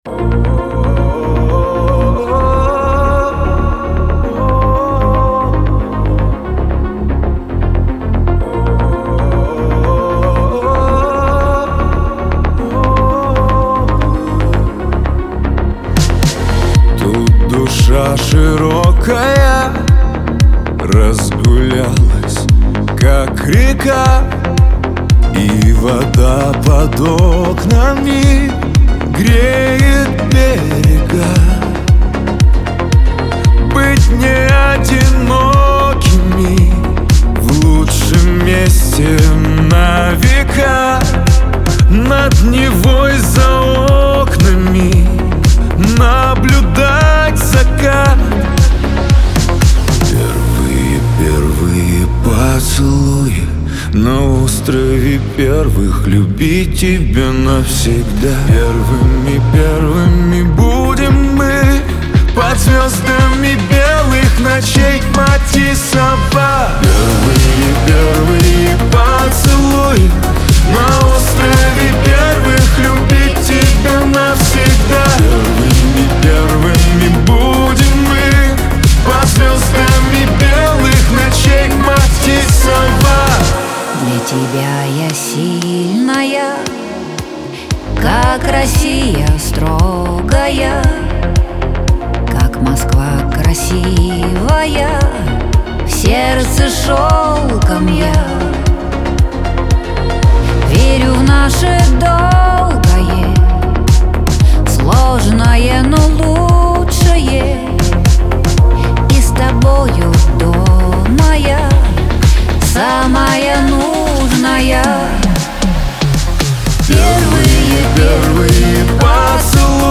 pop
дуэт , эстрада